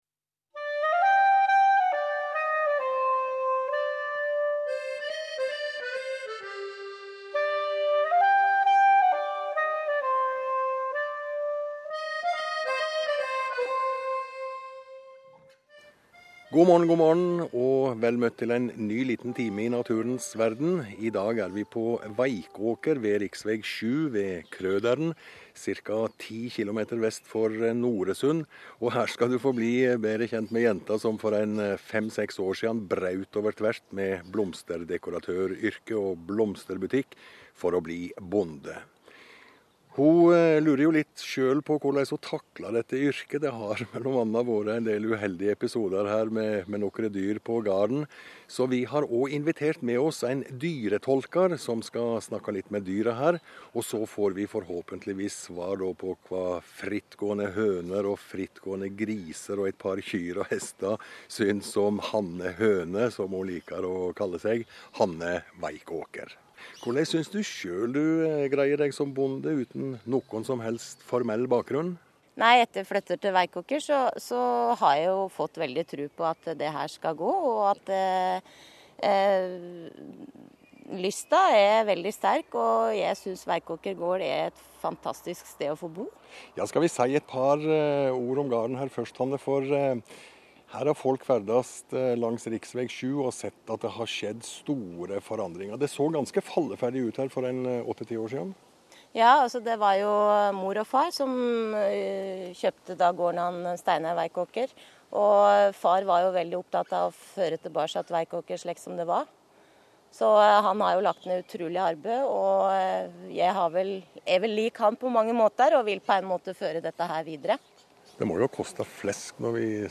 Søndag 8. juni 2008 var jeg med i radioprogrammet Naturens Verden fra Veikåker gård last ned utdrag…(23mb ) «